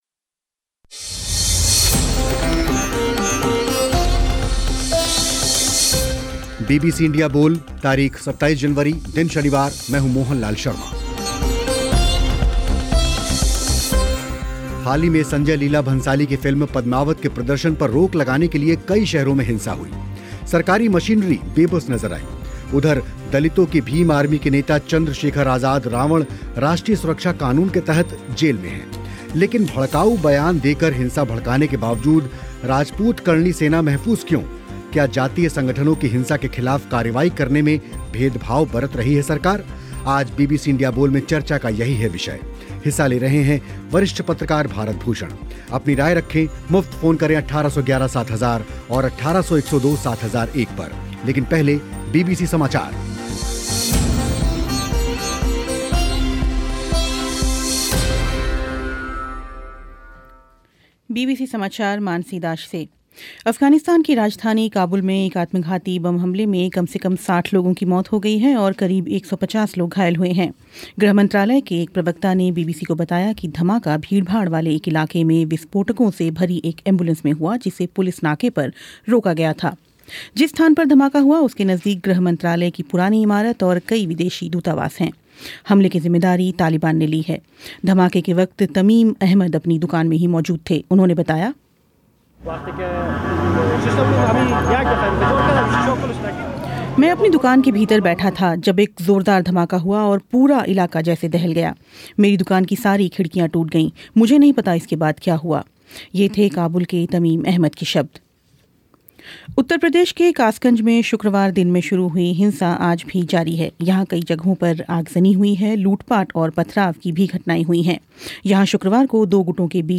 कार्यक्रम में चर्चा इसी मुद्दे पर हुई.